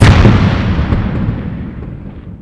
Expl08.wav